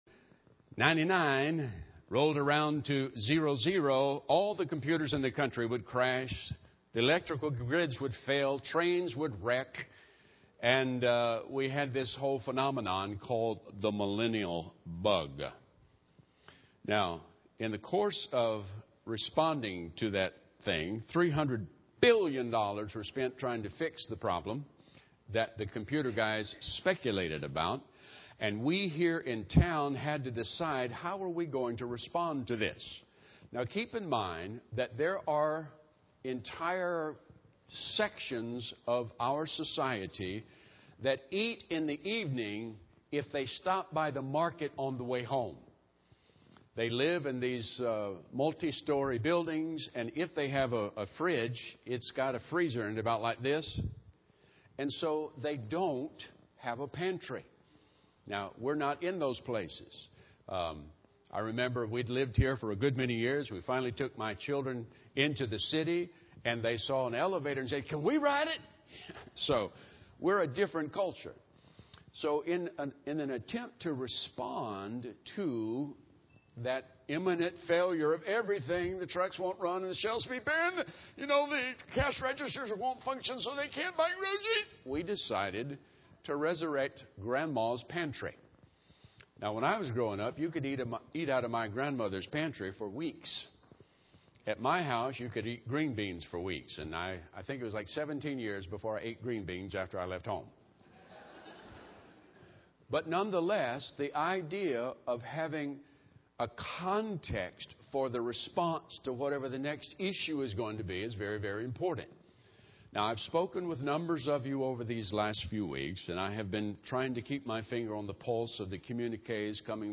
A sermon from 3/10/2013 presented at Our Father's House Assembly of God in California, MD. How will we handle these uncertain financial times?